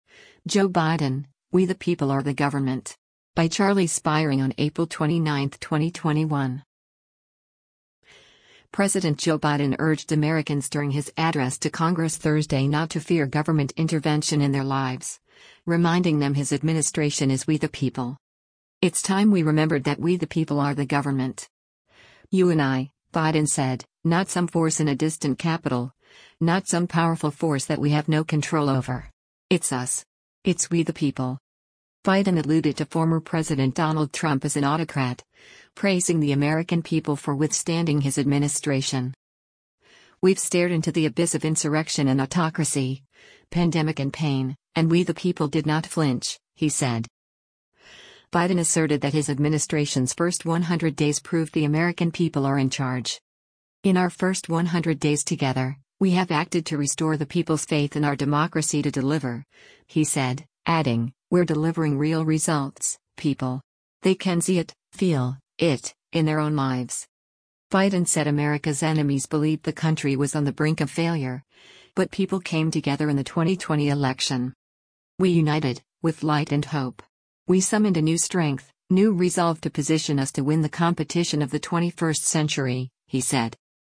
President Joe Biden urged Americans during his address to Congress Thursday not to fear government intervention in their lives, reminding them his administration is “We the people.”